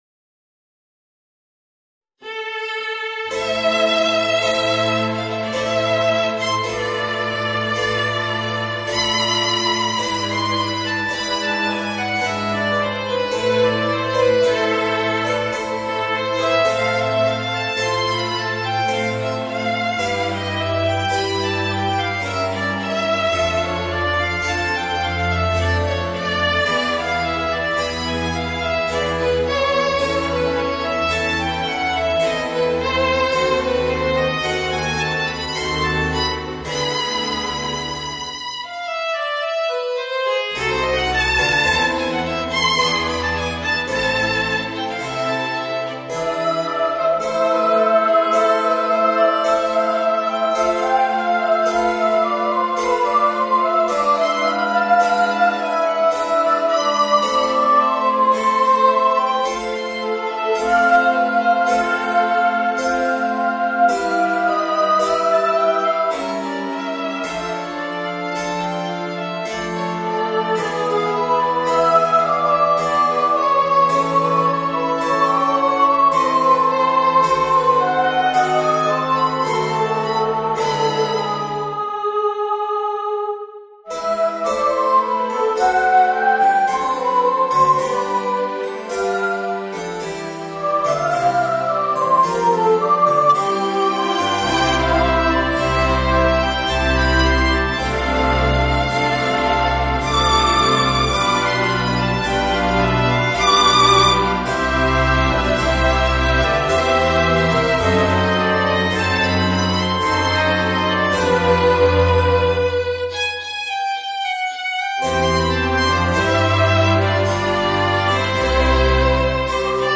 Voicing: Soprano and Flute and Orchestra